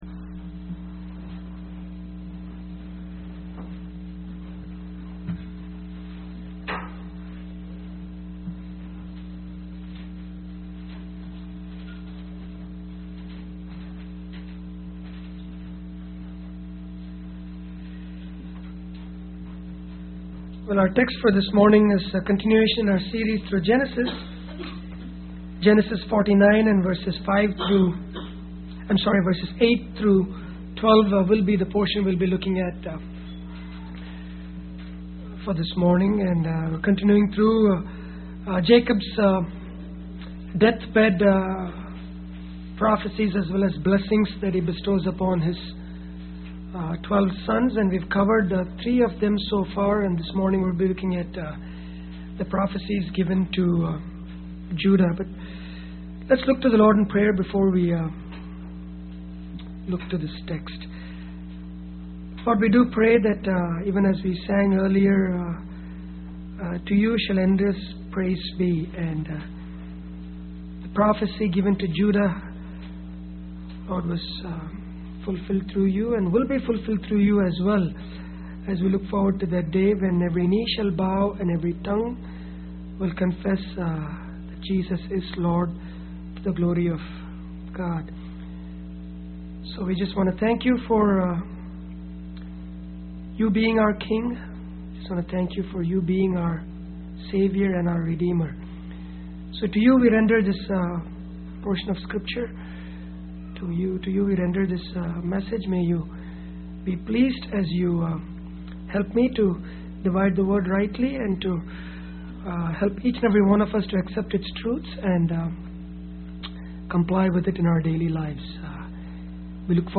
Bible Text: Genesis 49:8-12 | Preacher